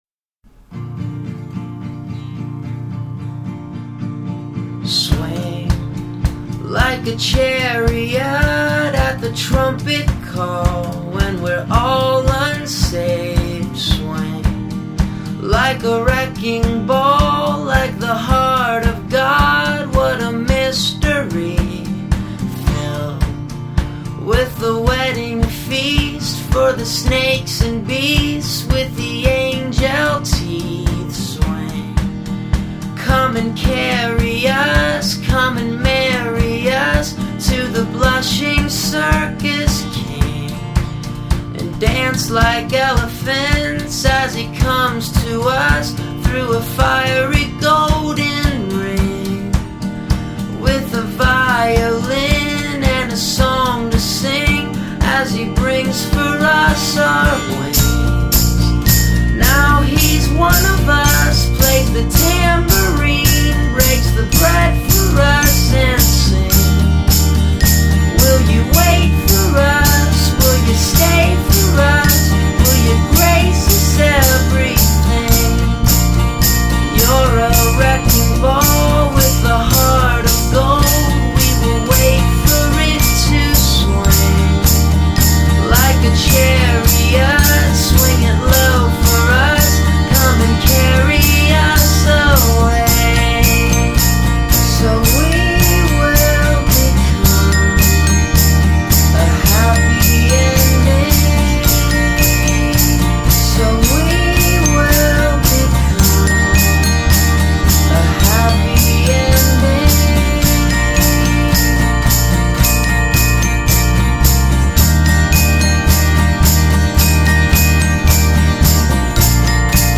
cheery and feel-good